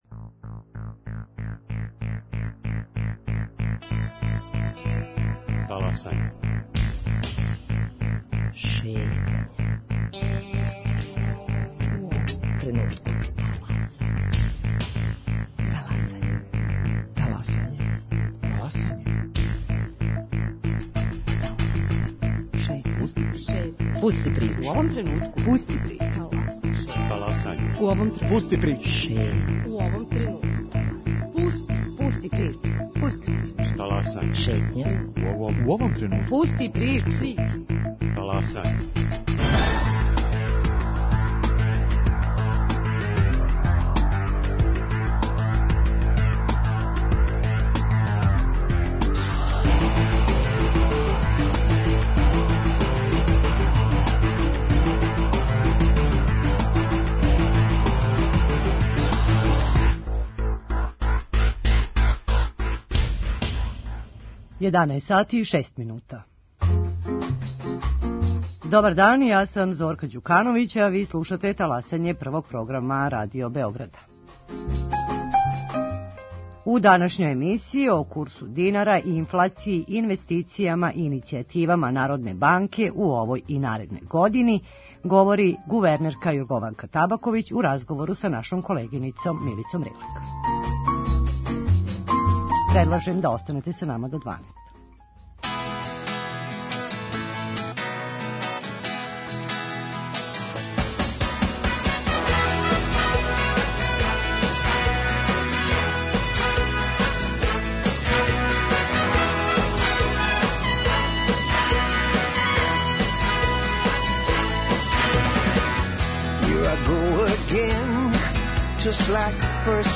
На ова и питања о инфлацији, инвестицијама Народне банке Србије одговара гувернер Јоргованка Табаковић у новогодишњем интервјуу за Радио Београд 1.